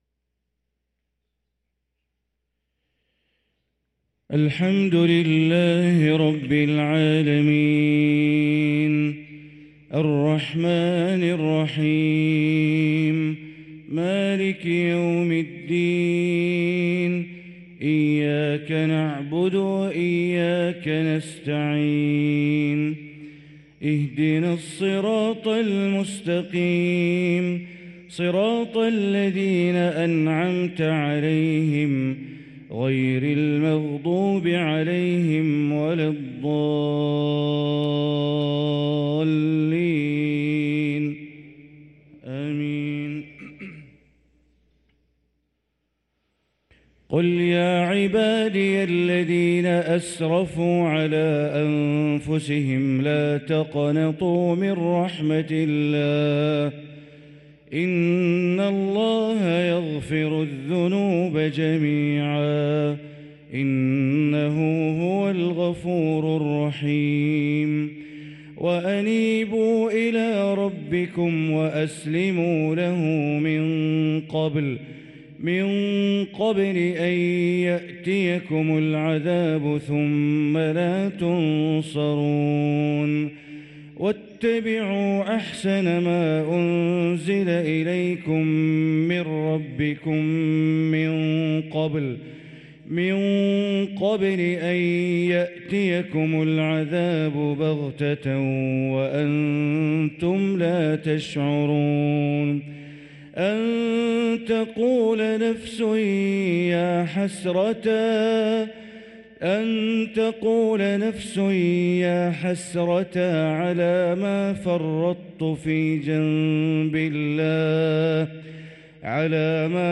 صلاة الفجر للقارئ بندر بليلة 15 رجب 1445 هـ